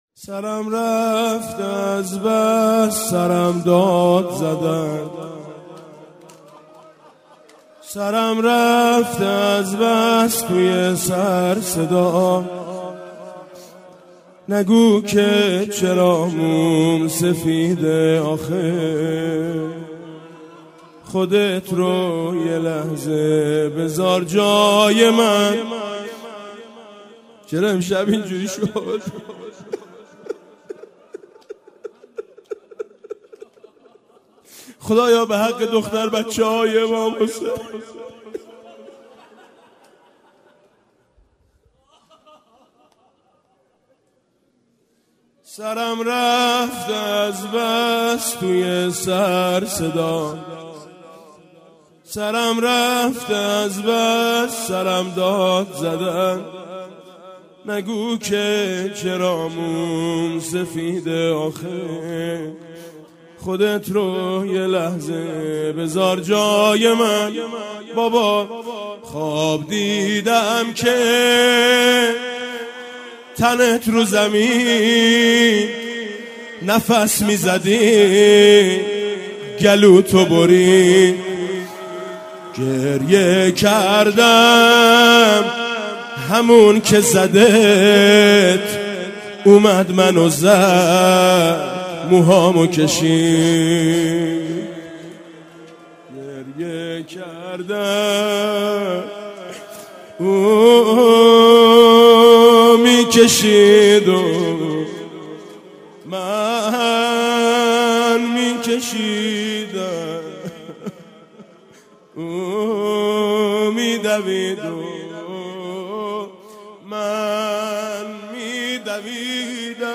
19رمضان98-روضه-بعد غارت ندیدی ما رو